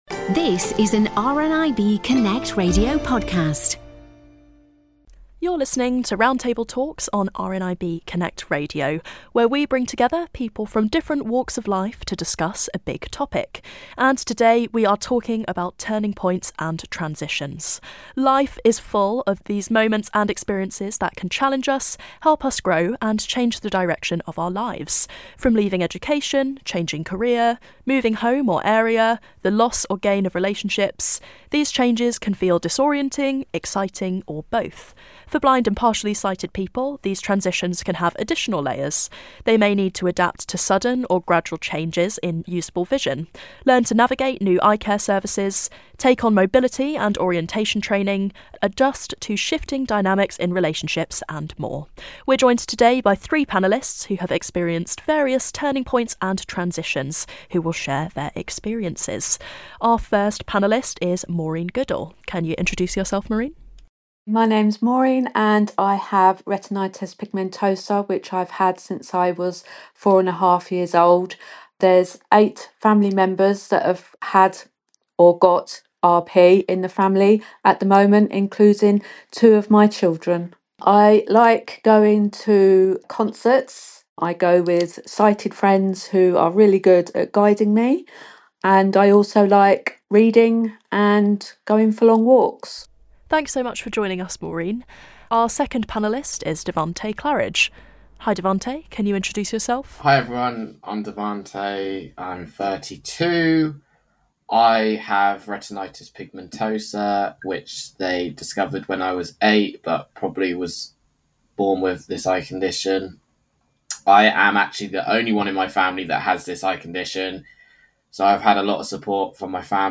S2 Ep1278: Turning Points And Transitions - Roundtable